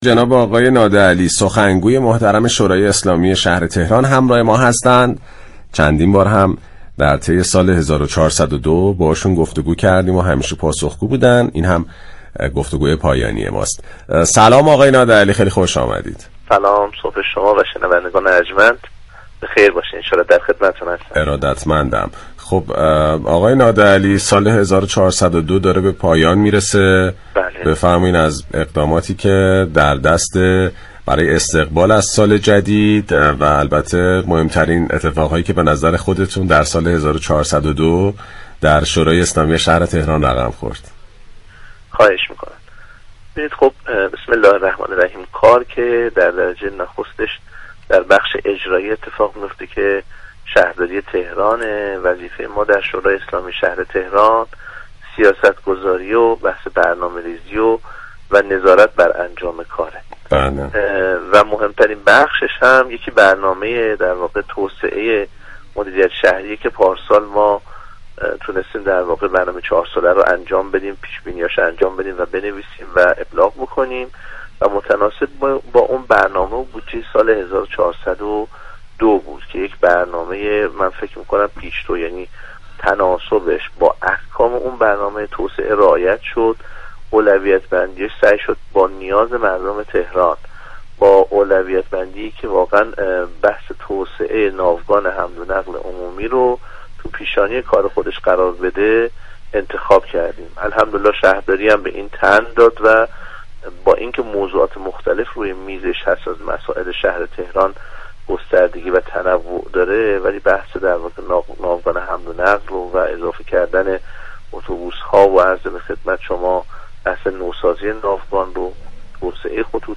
به گزارش پایگاه اطلاع رسانی رادیو تهران، علیرضا نادعلی سخنگوی شورای اسلامی شهر در گفت و گو با «شهر آفتاب» درخصوص مهمترین دستاوردهای شورای شهر در سال 1402 را اینگونه برشمرد: نوسازی و توسعه ناوگان حمل و نقل عمومی كشور یكی از مهمترین اهداف شورای اسلامی شهر تهران در سال 1402 بود و شهرداری تهران این سیاست‌گذاری را به خوبی در سال 1402 محقق كرد.